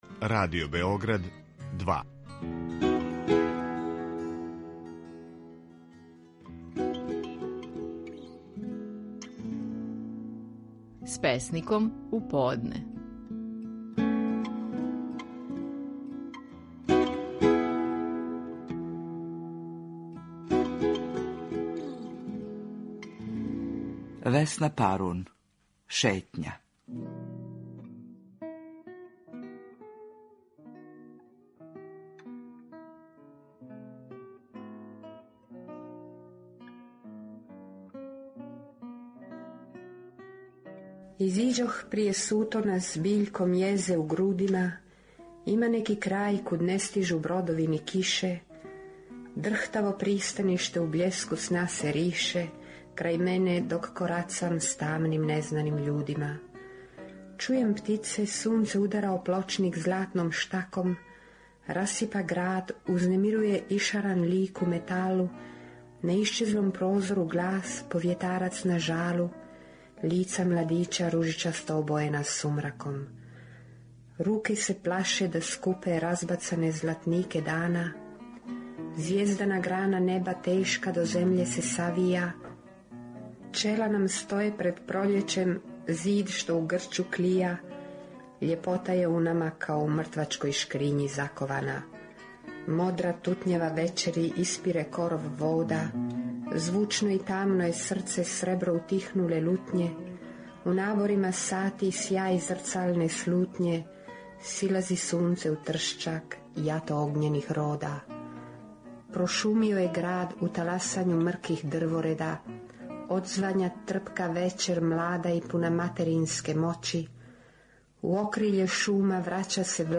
Стихови наших најпознатијих песника, у интерпретацији аутора.
Весна Парун говори песму „Шетња".